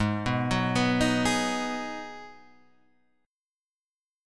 G# Minor 7th
G#m7 chord {4 2 4 4 4 4} chord